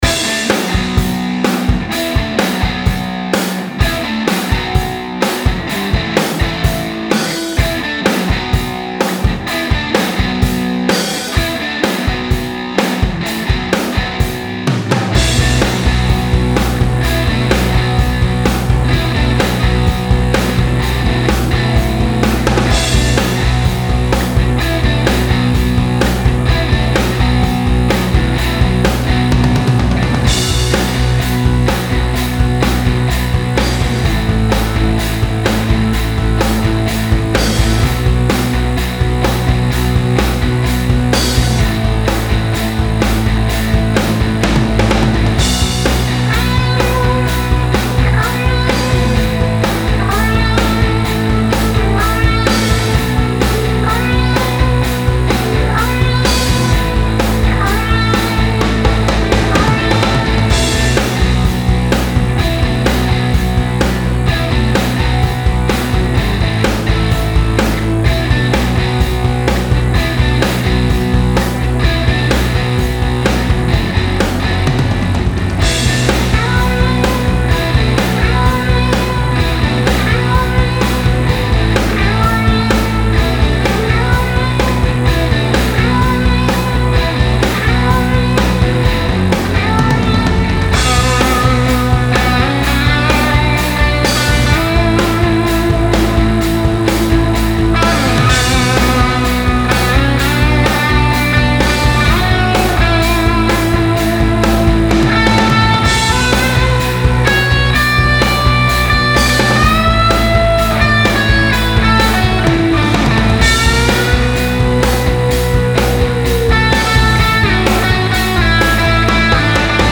Style Style Rock
Mood Mood Cool, Driving
Featured Featured Bass, Drums, Electric Guitar +2 more
BPM BPM 127